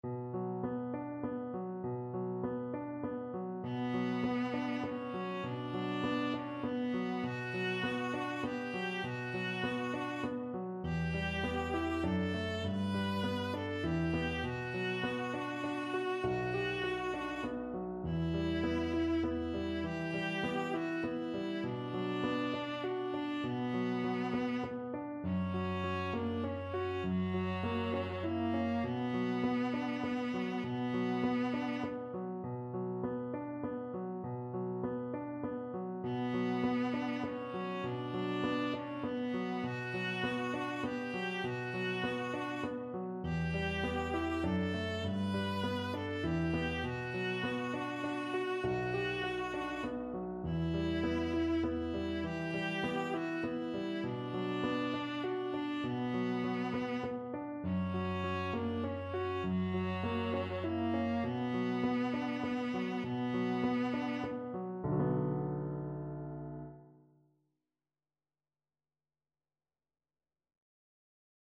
Viola
Traditional Music of unknown author.
3/4 (View more 3/4 Music)
B minor (Sounding Pitch) (View more B minor Music for Viola )
Moderato, gently